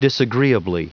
Prononciation du mot disagreeably en anglais (fichier audio)
Prononciation du mot : disagreeably